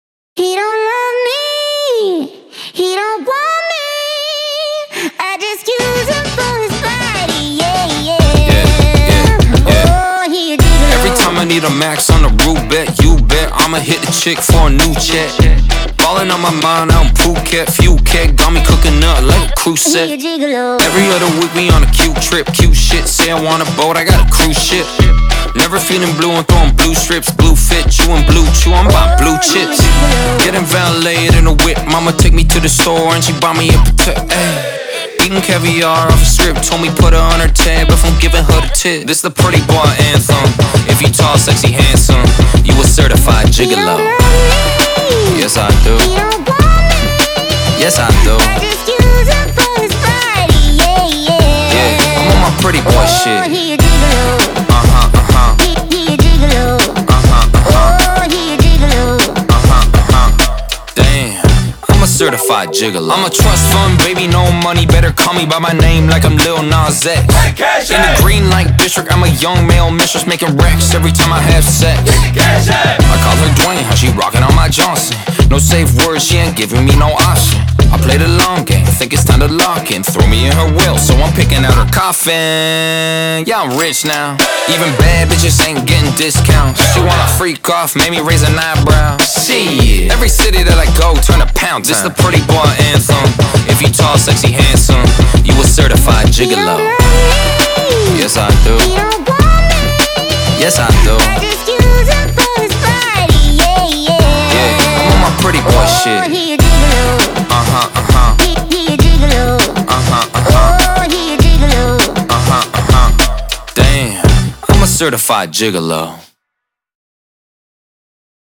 BPM100-100
Audio QualityPerfect (High Quality)
Rap song for StepMania, ITGmania, Project Outfox